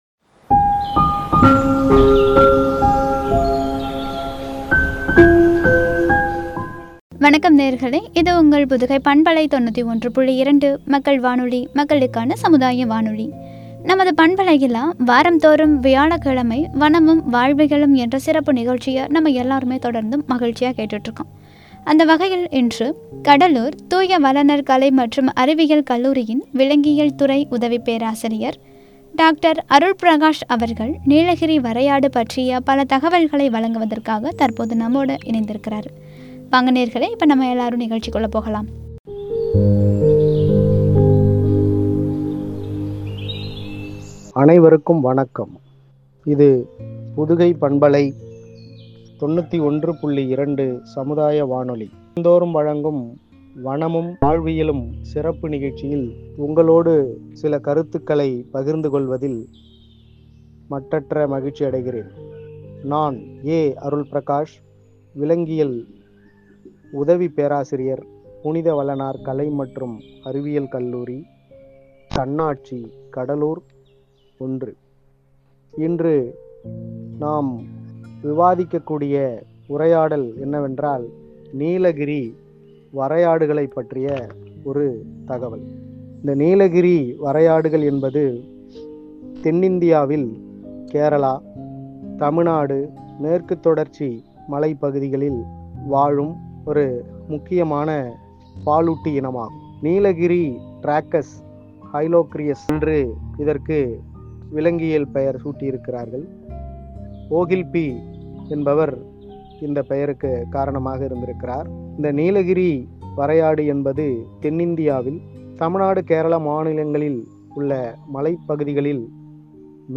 “நீலகிரி வரையாடு” (வனமும் வாழ்வியலும்- பகுதி 139) என்ற தலைப்பில் வழங்கிய உரையாடல்.